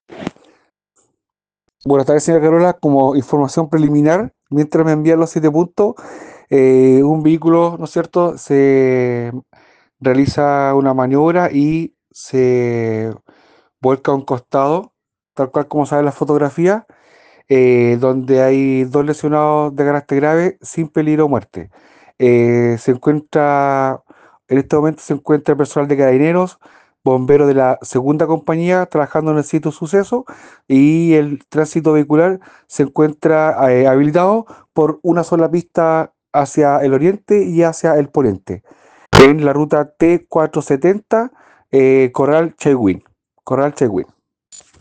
Oficial de Carabineros ..